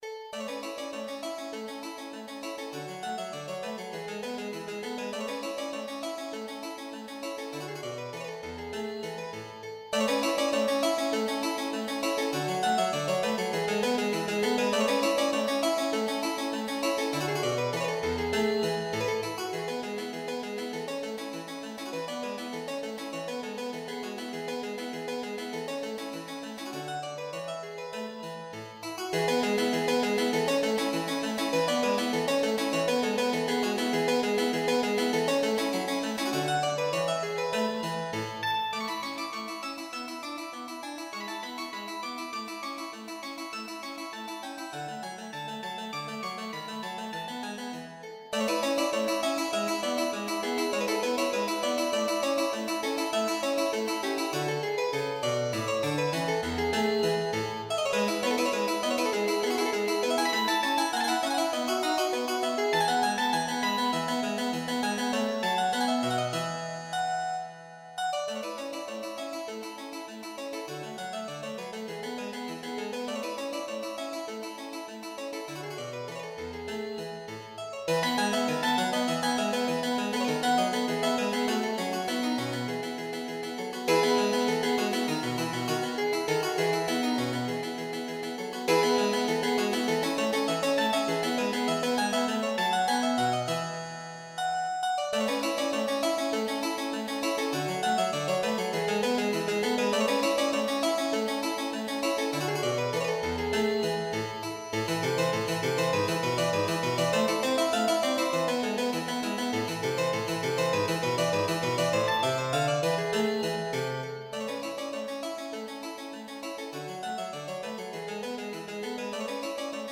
Rondo
II Rondo Vivace, (7-part ABACADA) (cannot find recording)